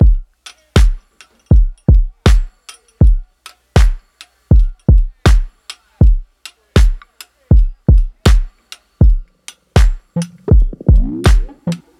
Photogenic Drums.wav